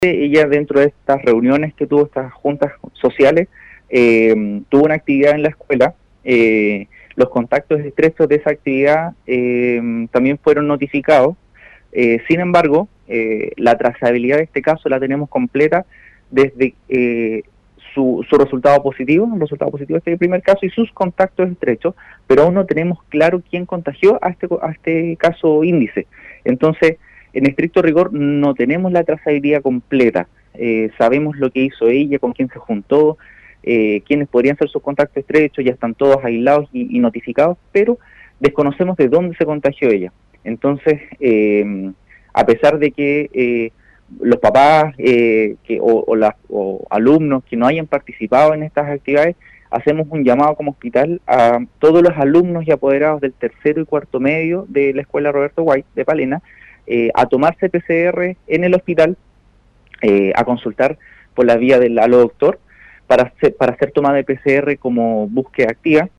en entrevista con radio Estrella del Mar de esa provincia